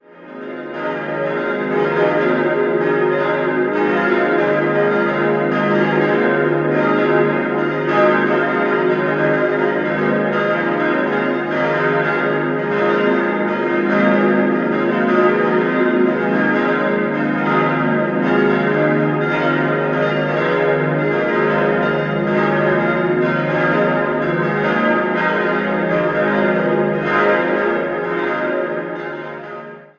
Dreieinigkeitsglocke es' 1.380 kg 1960 Bachert, Heilbronn Christusglocke f' 967 kg 1960 Bachert, Heilbronn Paulusglocke as' 653 kg 1960 Bachert, Heilbronn Lutherglocke b' 523 kg 1960 Bachert, Heilbronn Melanchtonglocke c'' 433 kg 1960 Bachert, Heilbronn Bekennerglocke es'' 292 kg 1960 Bachert, Heilbronn Taufglocke f'' 201 kg 1960 Bachert, Heilbronn